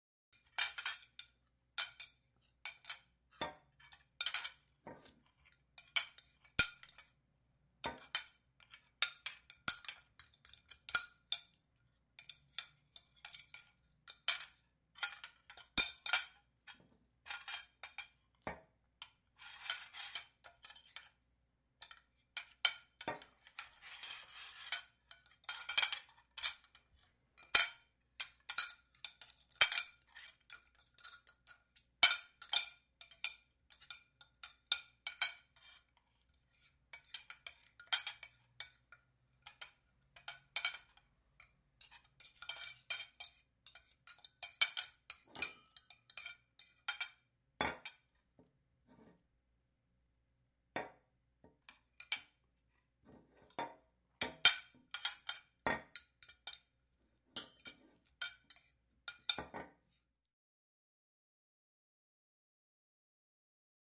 Restaurant - Dinnerware Walla, No Voices Silverware dish clinks isolated